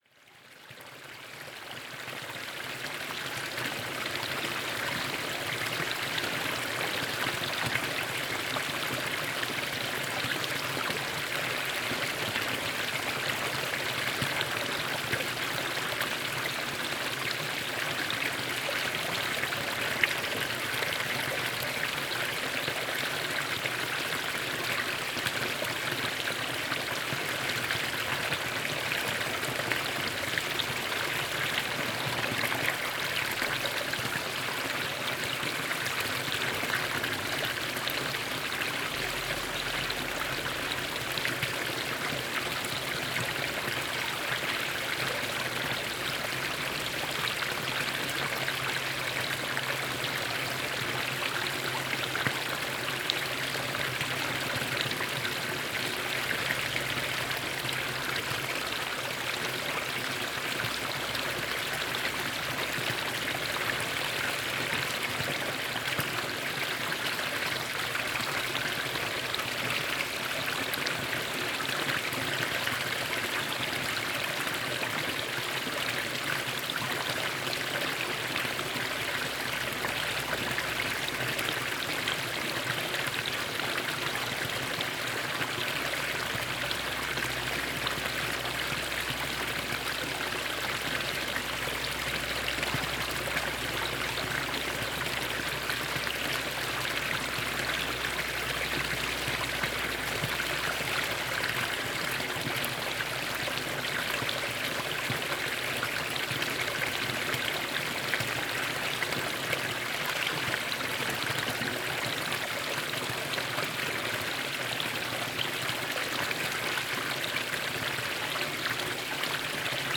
NODAR.00558 – Campo: Escoamento de água de fonte comunitária em Bassar
Paisagem sonora de escoamento de água de fonte comunitária em Bassar, Campo a 3 Março 2016.
Numa manhã soalheira de Março encontrámos em Bassar uma fonte comunitária, e um vai e vem de pessoas a encher cântaros com água de excelente qualidade.